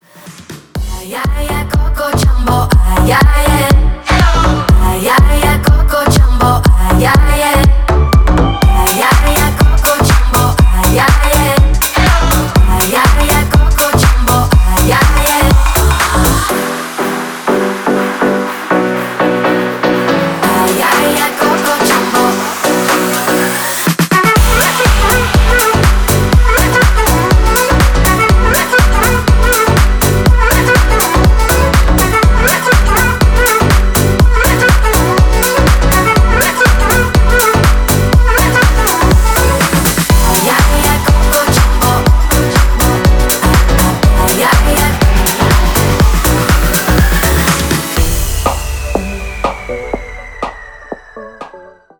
• Качество: 320, Stereo
EDM
электронная музыка
future house
красивый женский голос
house
танцевальные